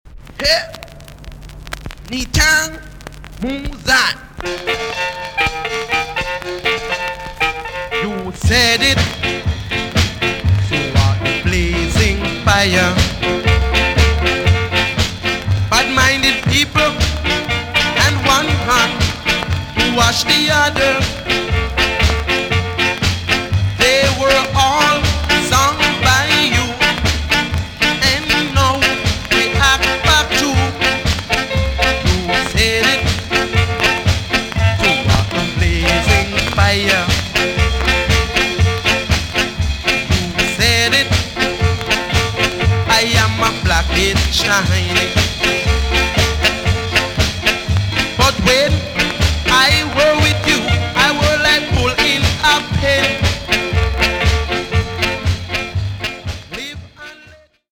TOP >SKA & ROCKSTEADY
VG+~VG ok 軽いチリノイズが入ります。
BIG HIT!!WICKED CLASH SKA TUNE!!